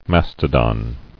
[mas·to·don]